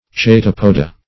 Chaetopoda \Ch[ae]*top"o*da\, n. pl.